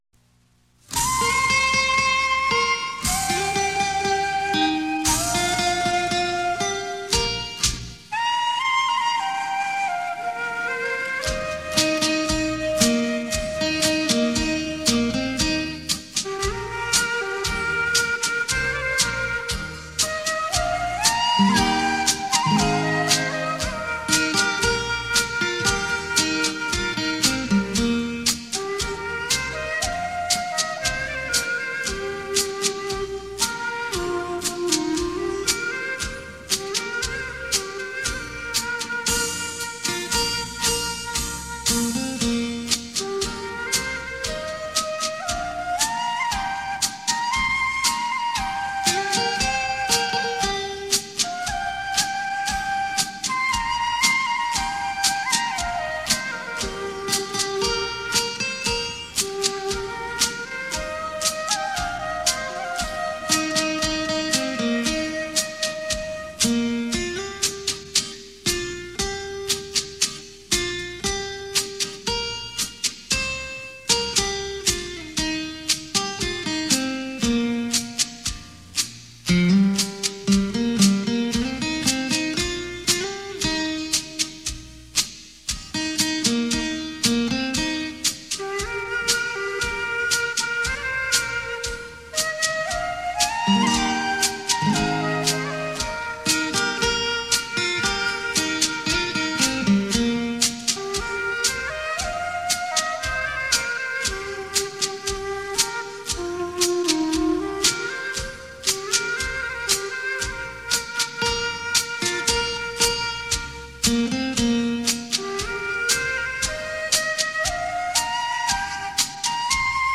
用洞箫悠扬的音色带您回味这曲曲脍炙人口扣人心弦的经典好歌。